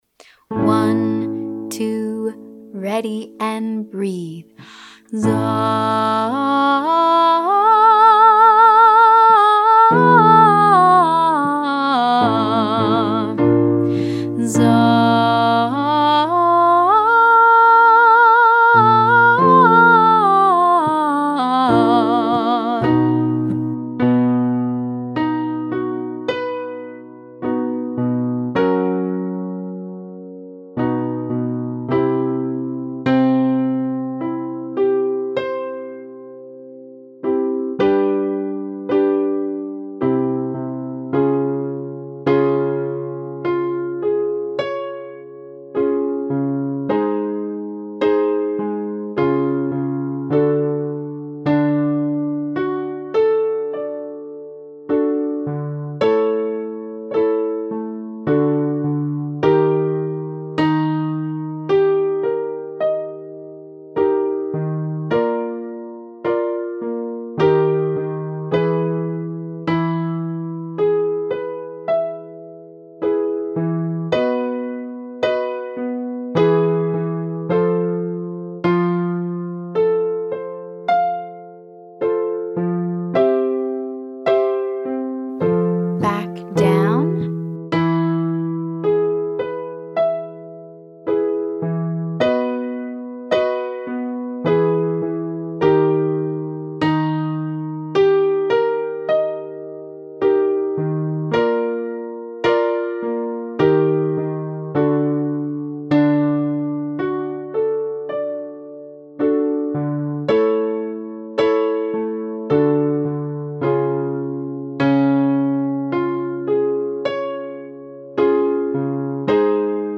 Za-ha-ha-ha
First we arpeggiate a chord upward.
The last part of the exercise is a rapid descent and release.